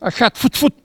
Cri pour chasser le chat ( prononcer le crti )